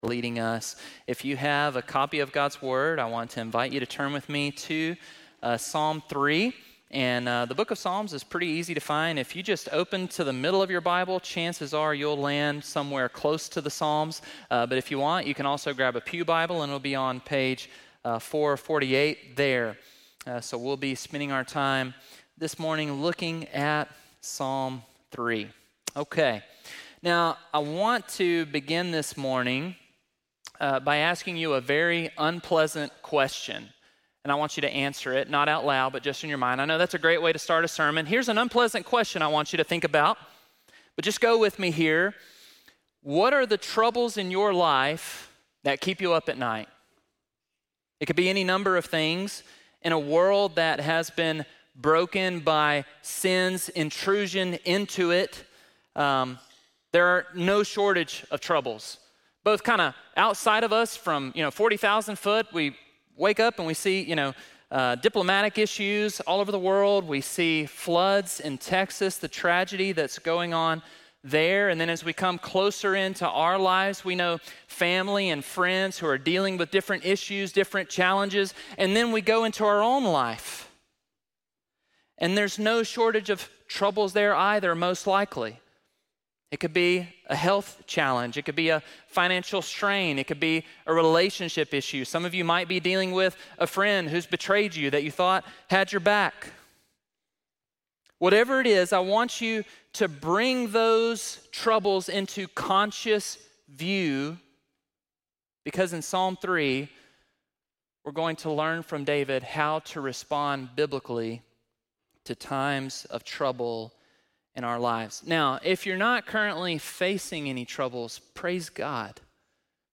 7.6-sermon.mp3